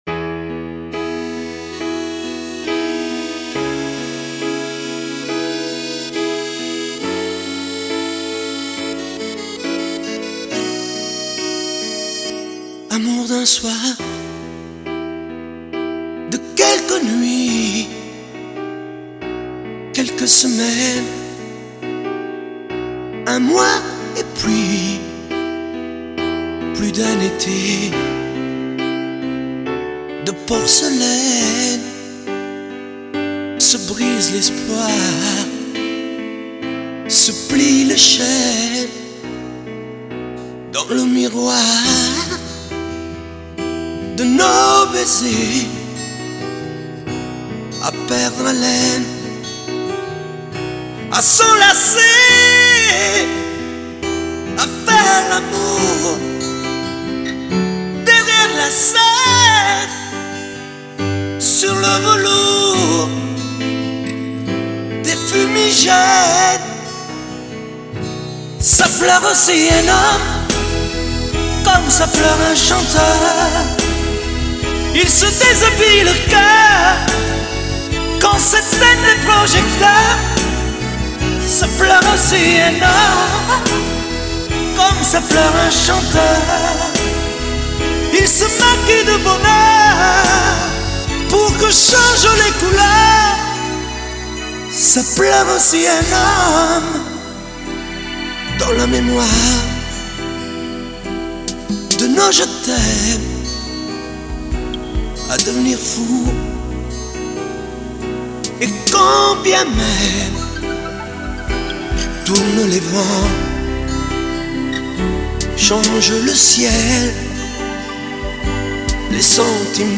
Чуть уменьшил уровень (зашкаливало за норму).